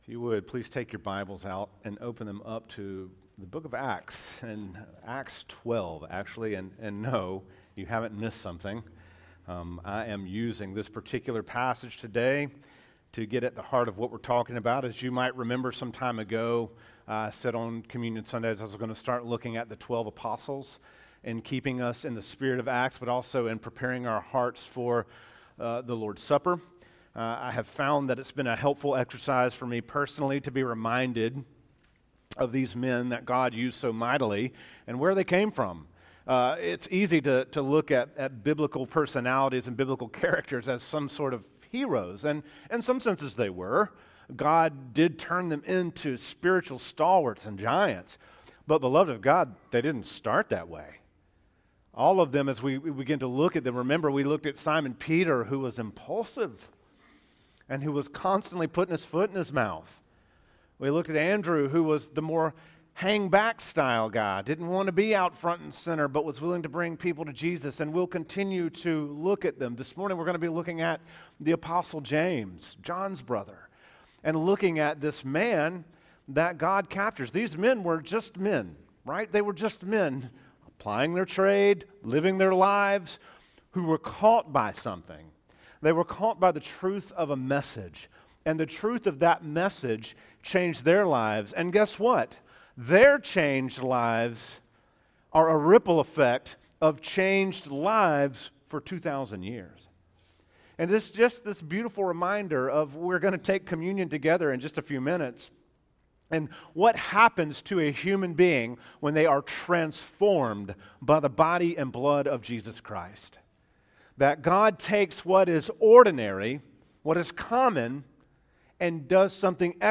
teaches from the series: Acts, in the book of Acts, verses 12:1 - 12:5